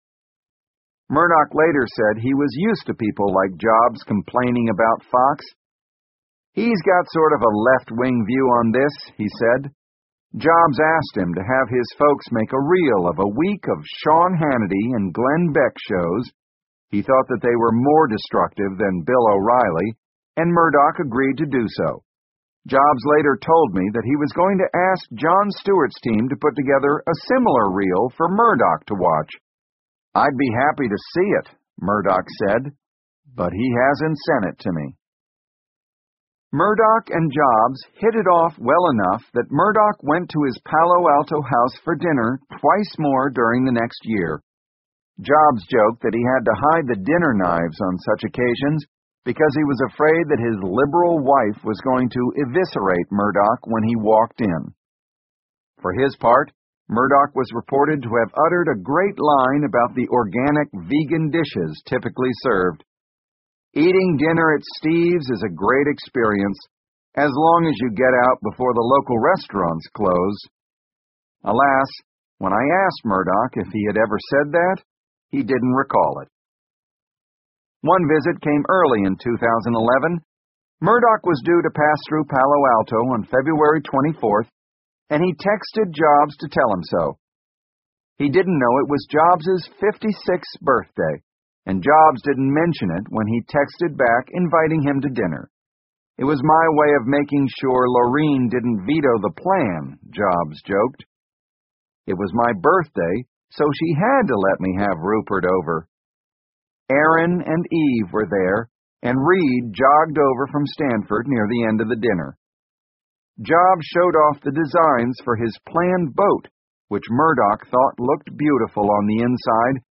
在线英语听力室乔布斯传 第708期:出版和新闻(10)的听力文件下载,《乔布斯传》双语有声读物栏目，通过英语音频MP3和中英双语字幕，来帮助英语学习者提高英语听说能力。
本栏目纯正的英语发音，以及完整的传记内容，详细描述了乔布斯的一生，是学习英语的必备材料。